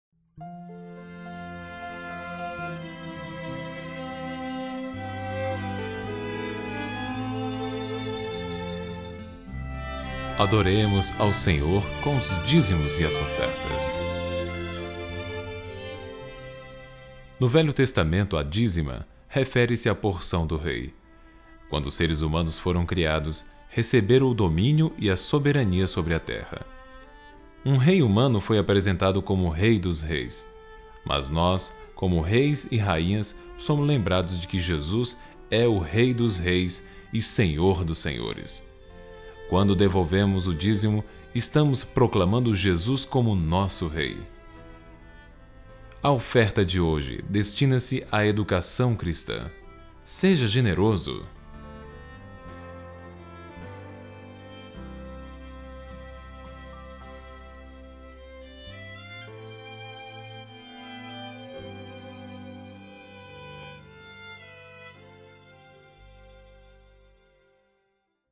arquivo de áudio (no formato wma) foi tocado em algumas igrejas adventistas do 7º dia de um determinado Campo antes de os diáconos recolherem os dízimos e as ofertas.
Então o teólogo esperto que escreveu essas palavras para o locutor ler, "contextualizou" -- como eles dizem -- as injustas dízimas do rei para os dias de hoje dizendo que o Rei Jesus recebe dízimos.